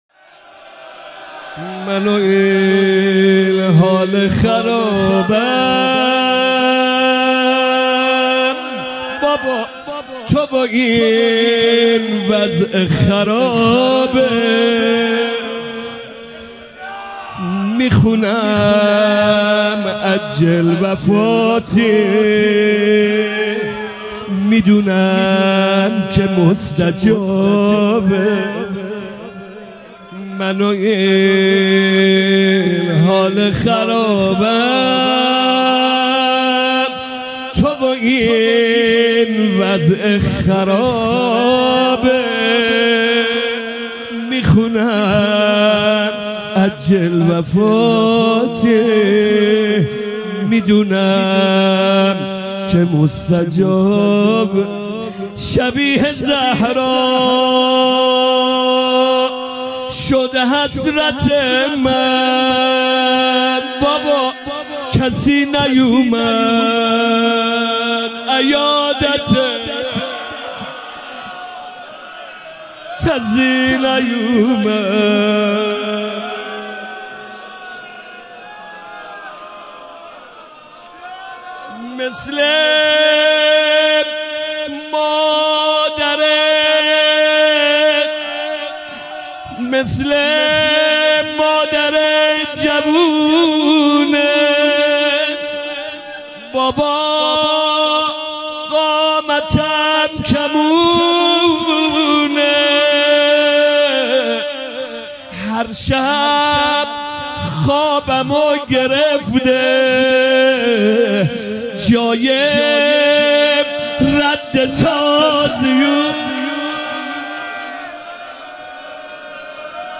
فاطمیه 96 - شب سوم - زمزمه - من و این حال خرابم